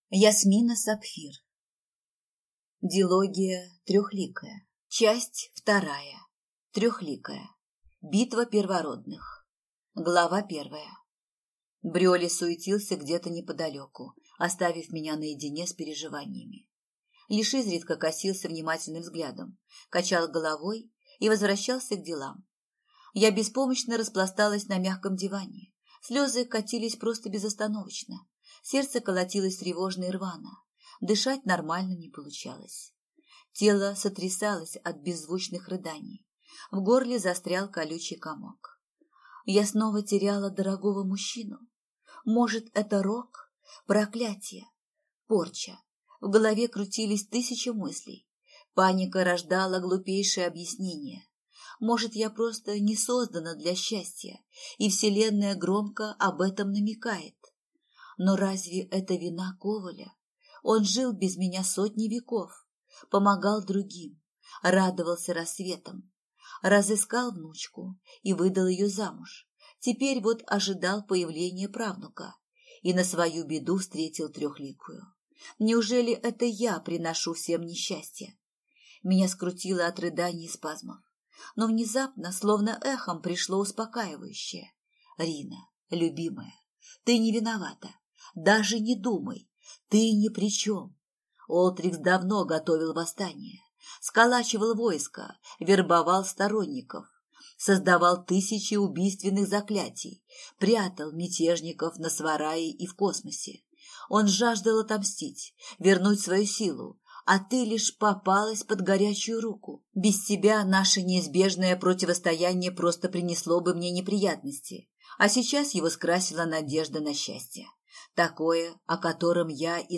Аудиокнига Трехликая. Битва первородных | Библиотека аудиокниг